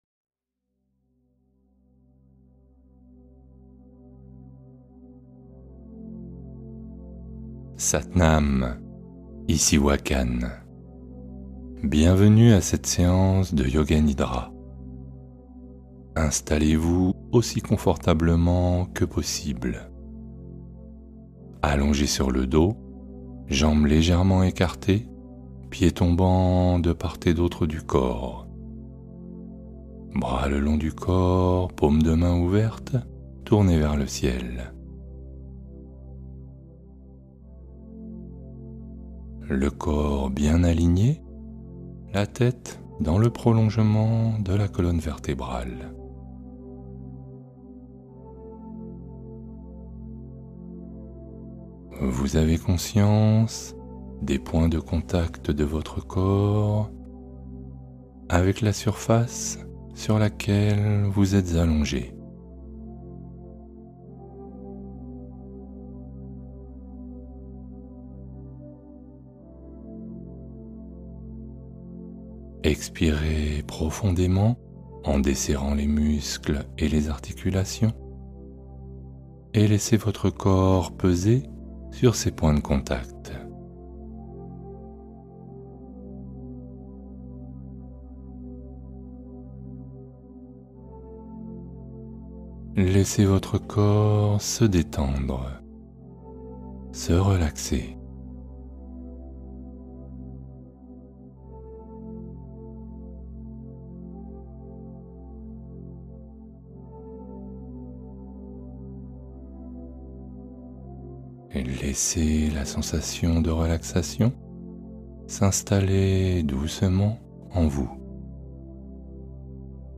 Bulle de calme : relaxation profonde pour le sommeil et la douleur